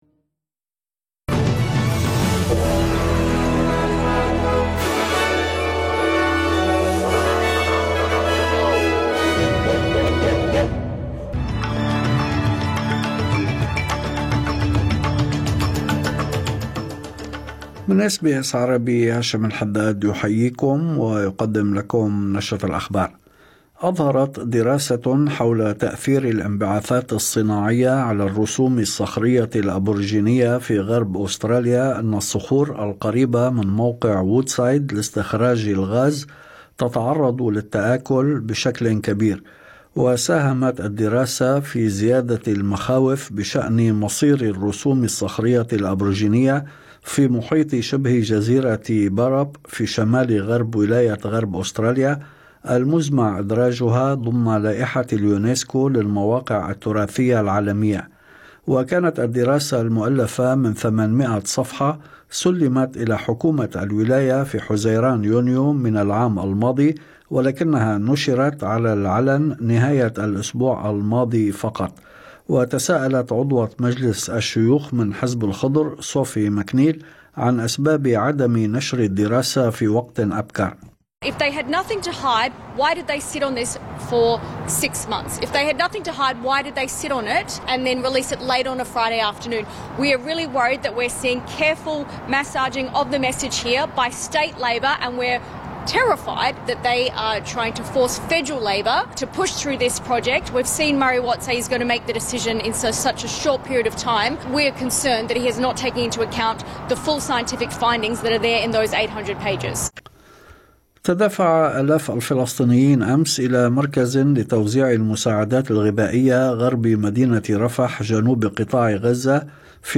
نشرة أخبار الظهيرة 28/5/2025